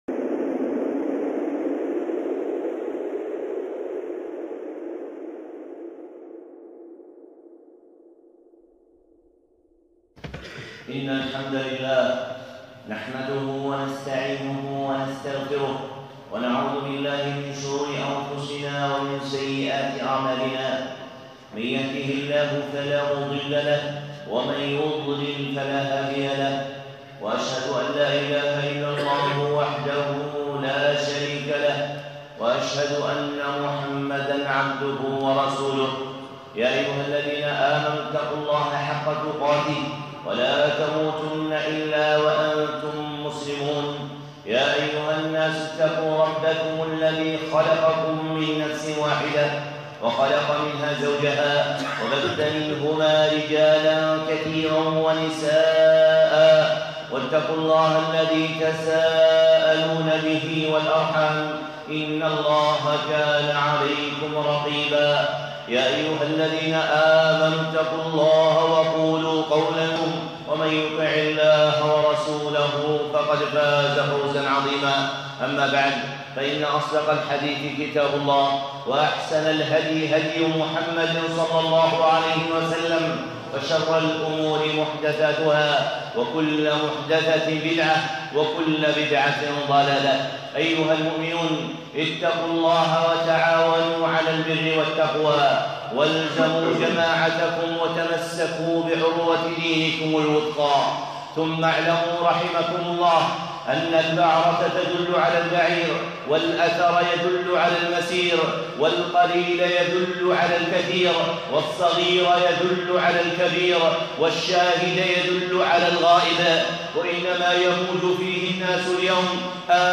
خطبة (من الامتحان الأكبر)